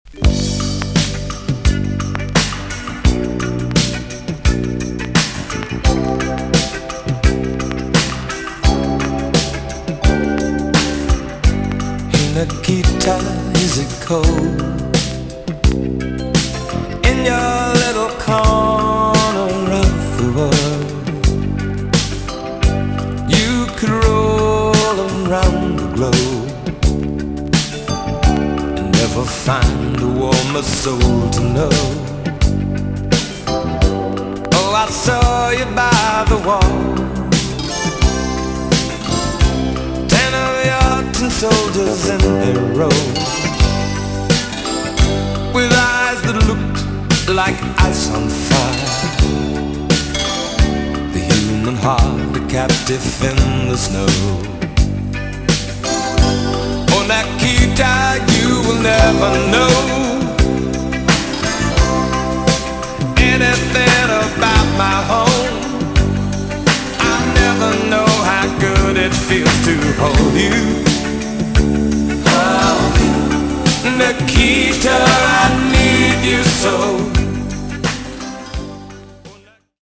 EX-~VG+ 少し軽いチリノイズが入りますが良好です。
NICE POP ROCK TUNE!!